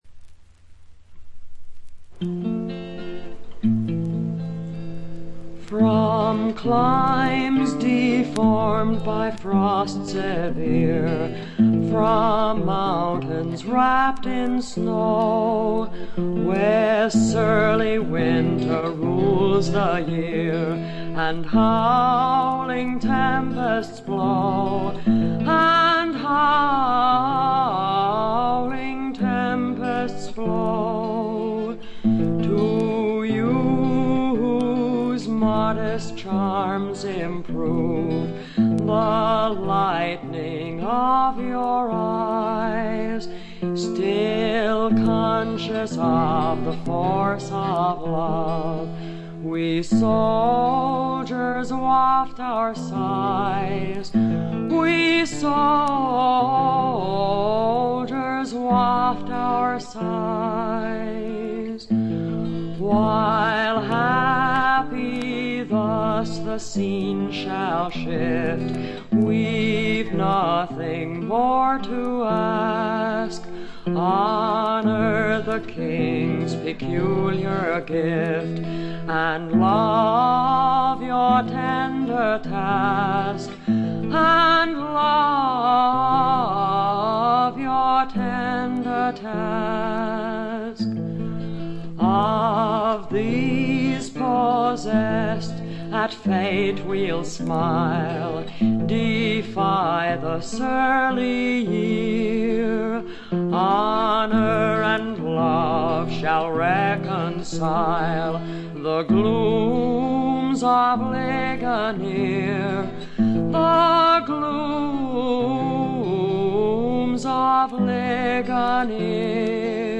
軽微なバックグラウンドノイズにチリプチ少し。散発的なプツ音2-3回。
魅力的なヴォイスでしっとりと情感豊かに歌います。
試聴曲は現品からの取り込み音源です。
Recorded At - WDUQ, Pittsburgh, PA